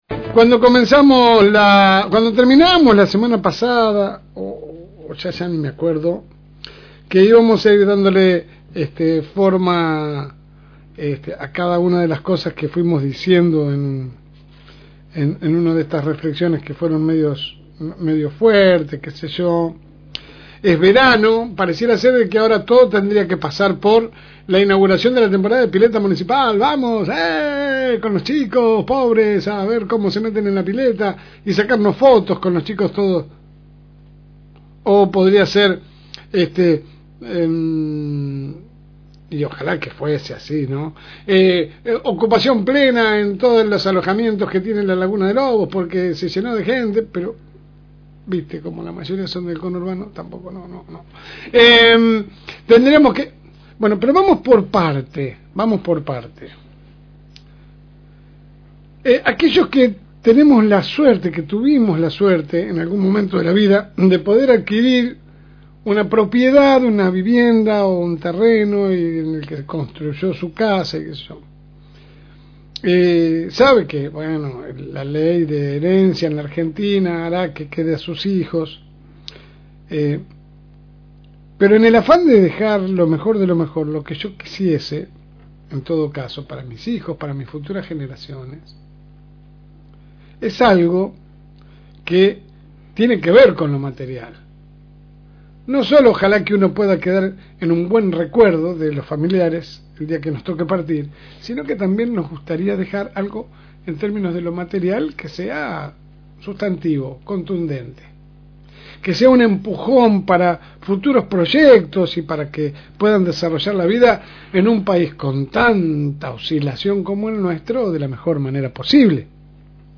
AUDIO – Editorial de la LSM.